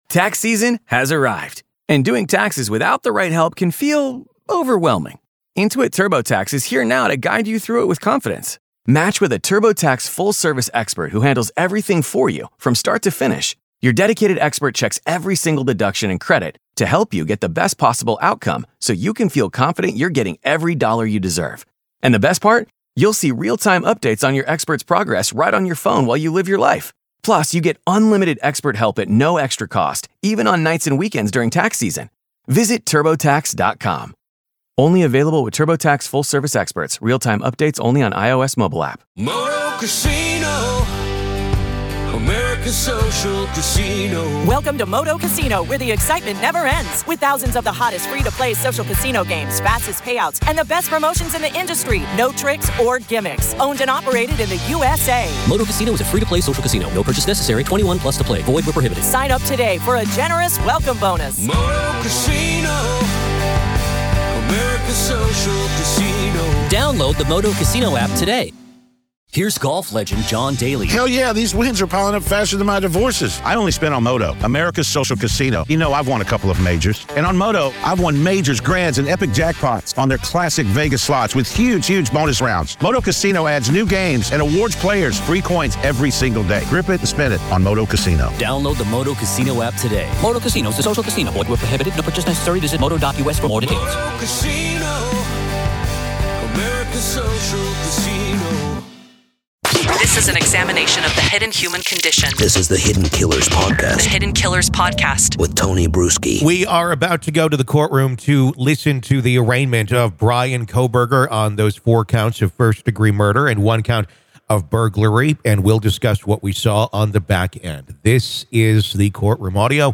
LISTEN: The Arraignment Of Bryan Kohberger
During the arraignment proceedings for Bryan Kohberger, he was officially charged with four counts of first-degree murder. Despite the gravity of these charges, Kohberger remained silent throughout the hearing.